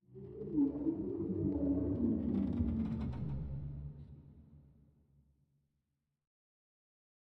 Minecraft Version Minecraft Version snapshot Latest Release | Latest Snapshot snapshot / assets / minecraft / sounds / ambient / nether / crimson_forest / shroom2.ogg Compare With Compare With Latest Release | Latest Snapshot